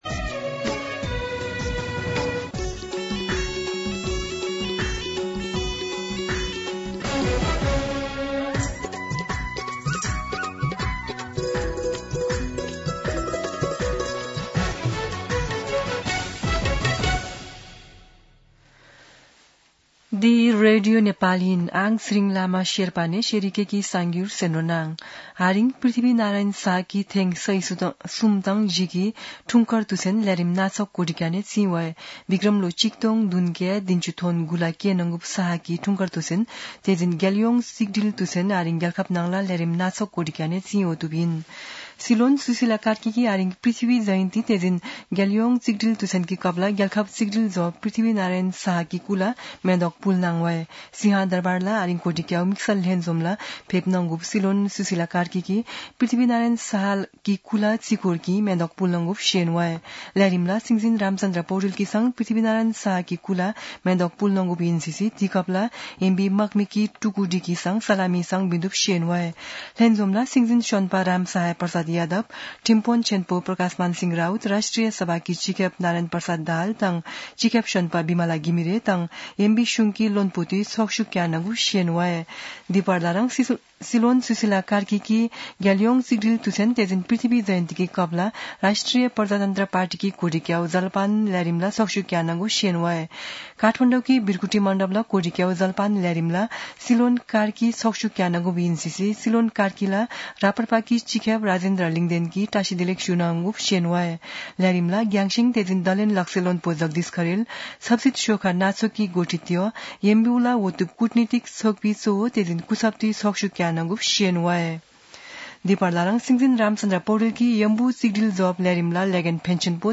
शेर्पा भाषाको समाचार : २७ पुष , २०८२
Sherpa-News-.mp3